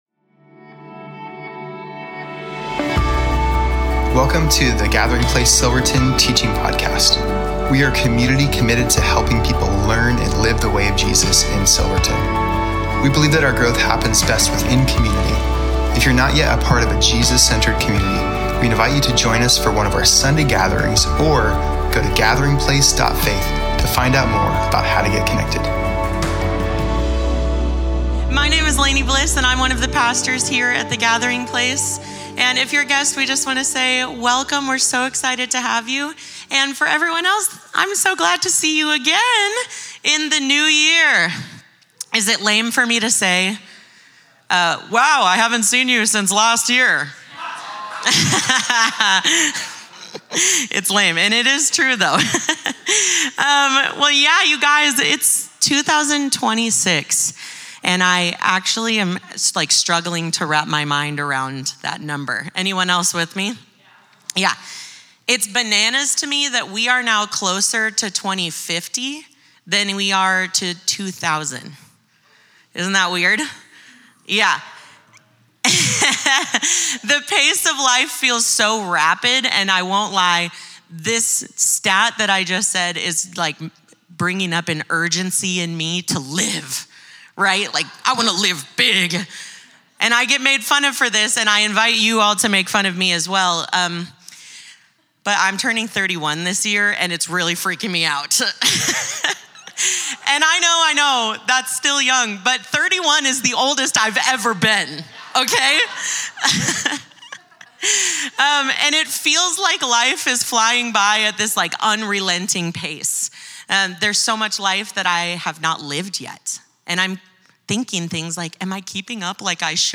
Home About Connect Events Sermons Give Nothing Is Wasted January 4, 2026